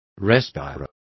Complete with pronunciation of the translation of respire.